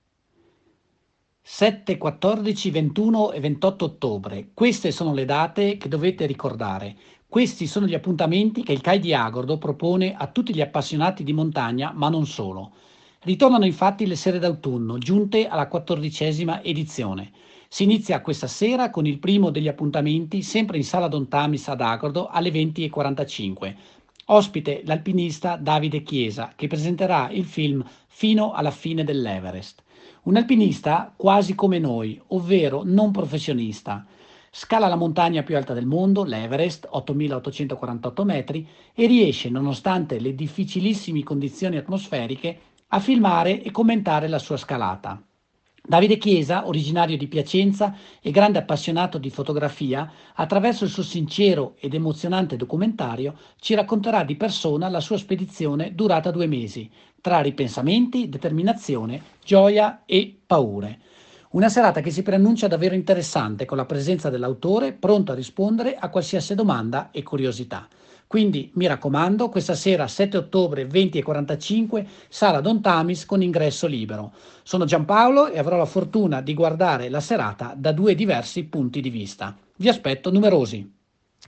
LA PRESENTAZIONE